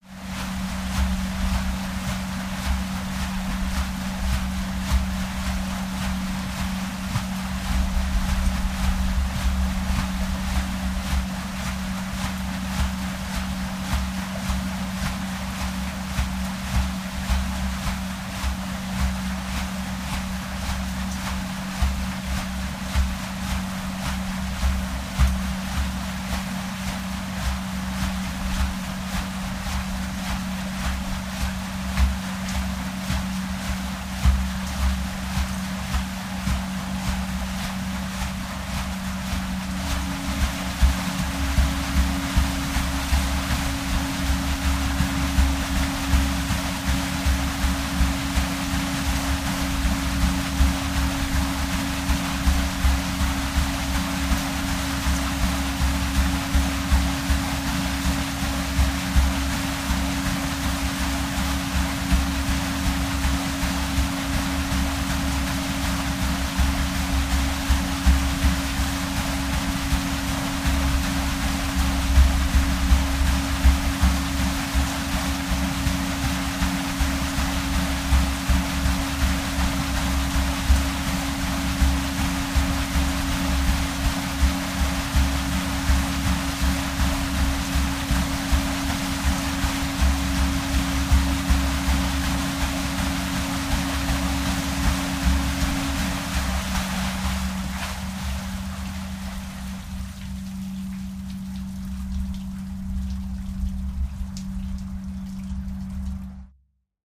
Ship, Paddlewheel Boat Water, Churn Boat, Paddlewheel